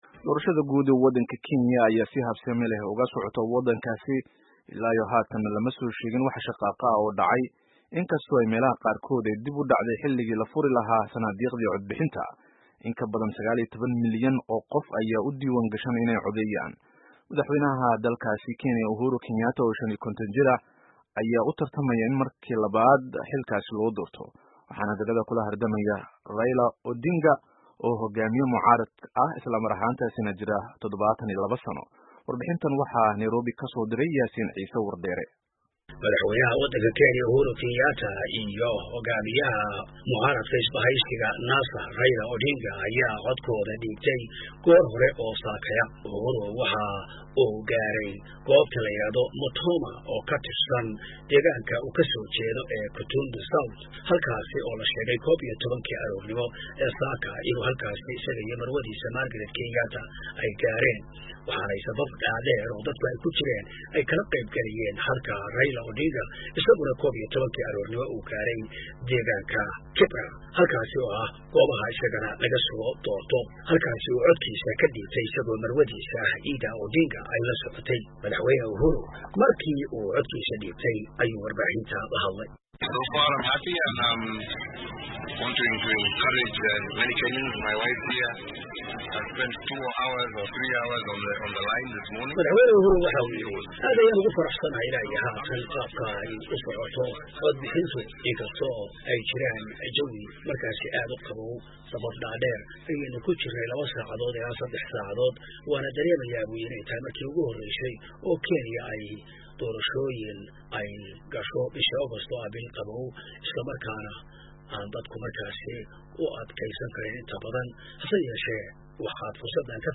Warbixino: Doorashada Kenya